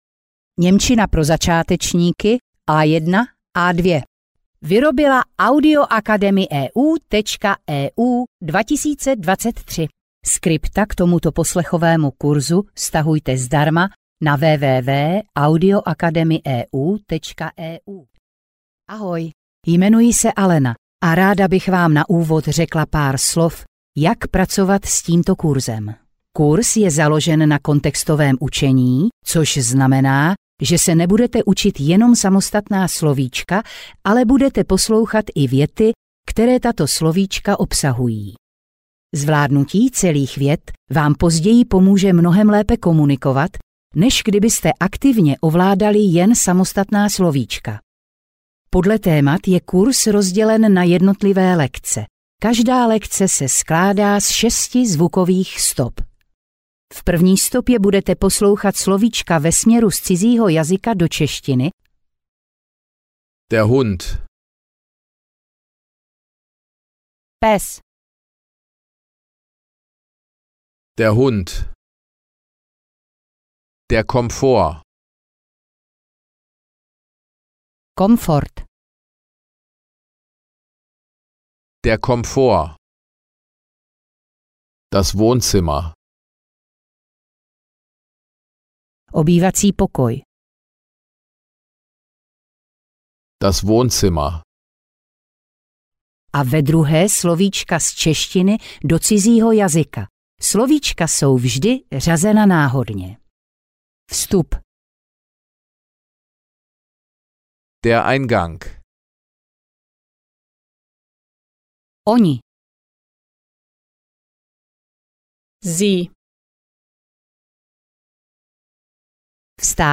Němčina pro začátečníky A1-A2 audiokniha
Ukázka z knihy